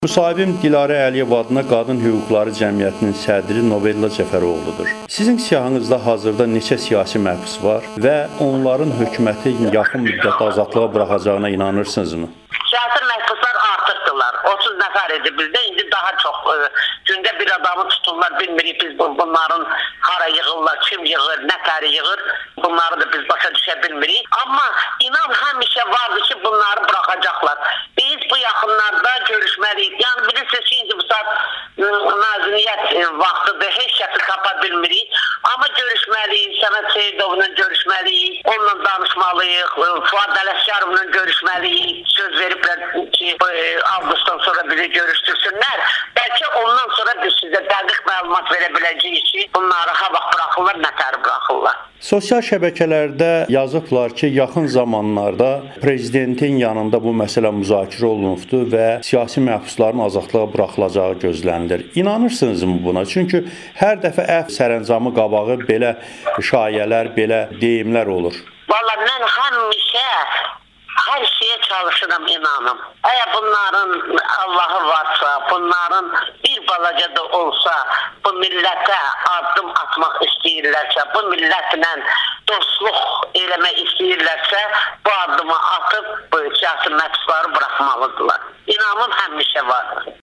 Siyasi məhbus problemi yenidən gündəmdədir - hüquq müdafiəçilərinin Amerikanın Səsinə müsahibələri [audio-müsahibə]
Bunların allahı varsa siyasi məhbusları buraxmalıdırlar [Audio-müsahibə]